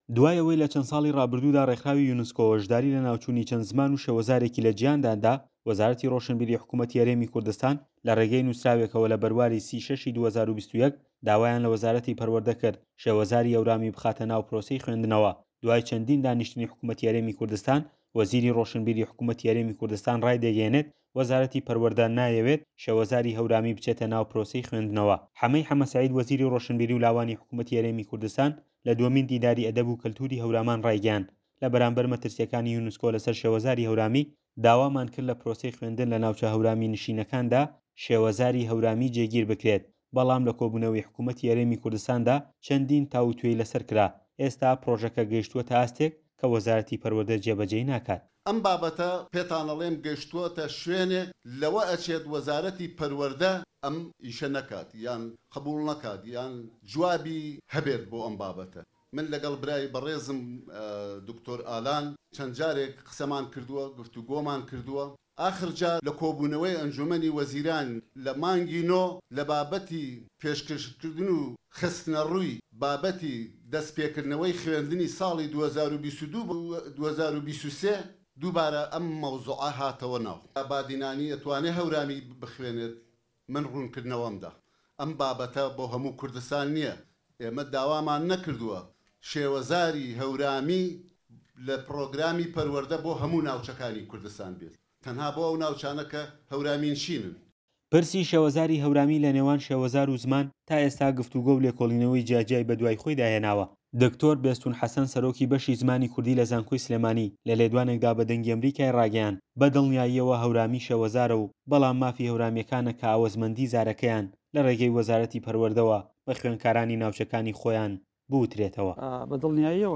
دەقی ڕاپۆرتی